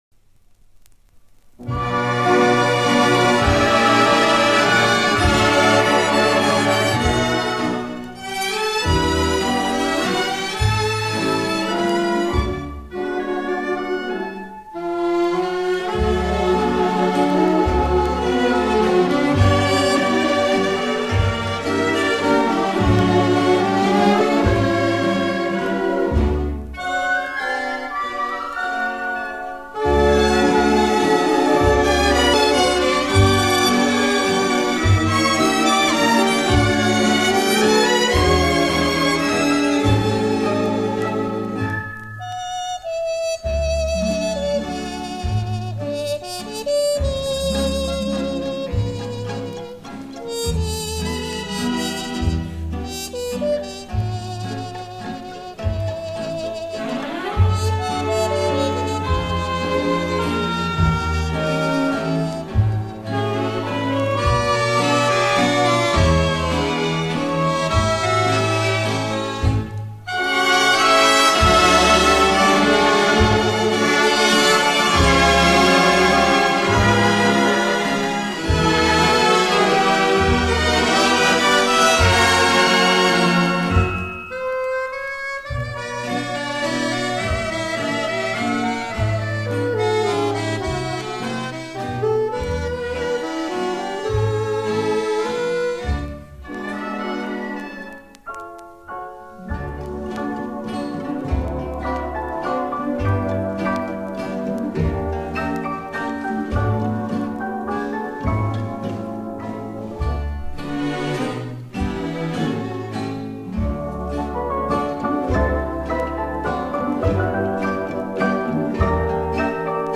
вальс-бостон